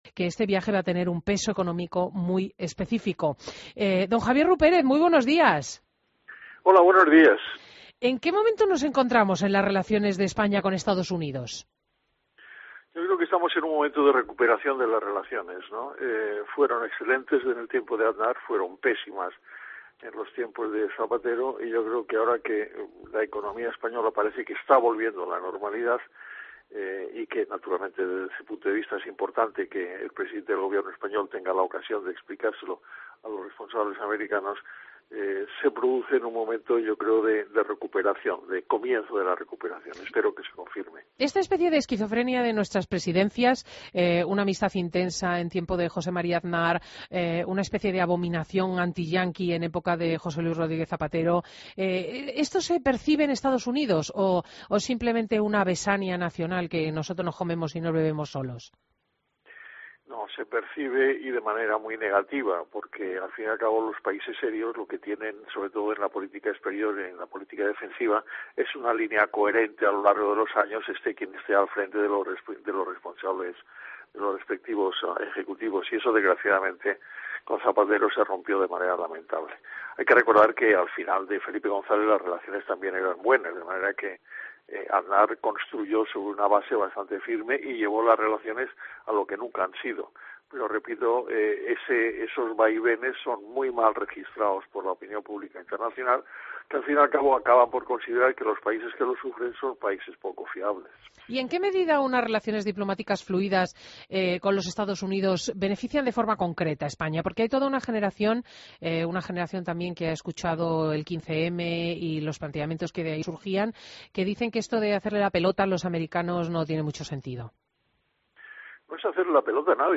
Entrevista a Javier Rupérez, diplomático y ex embajador